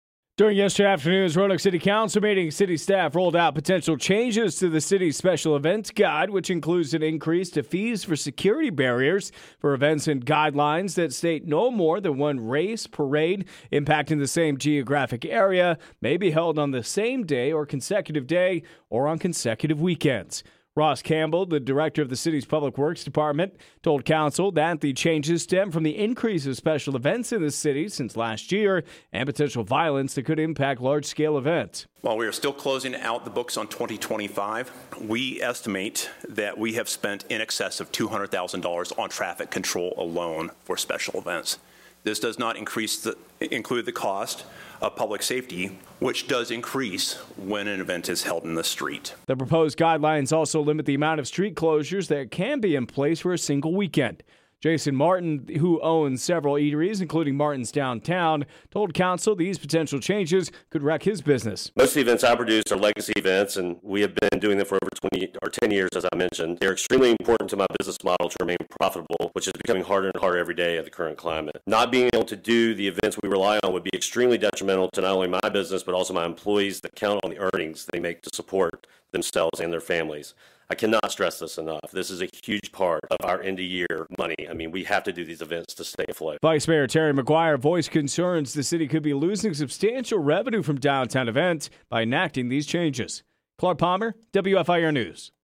During Tuesday’s Roanoke City Council meeting, city staff presented potential modifications to the special events guide that include increased fees for security barriers and new restrictions on the frequency and location of downtown events.